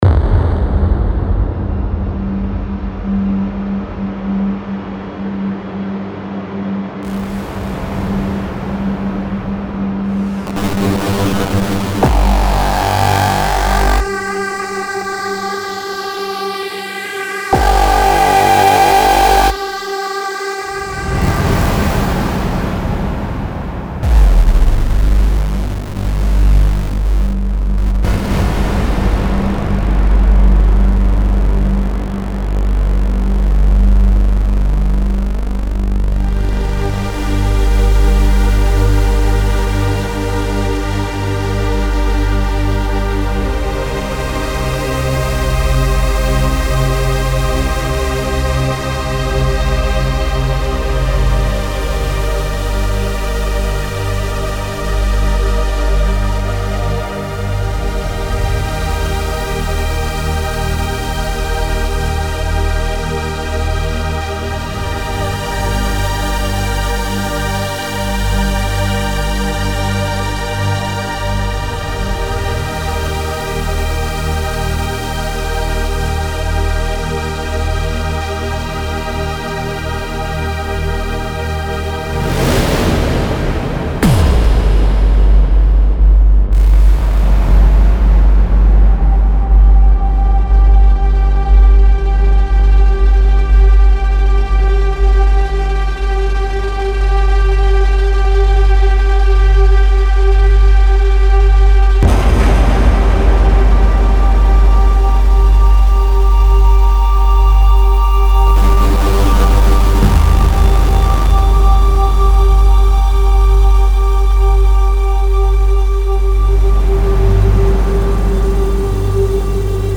Sound Effects Packs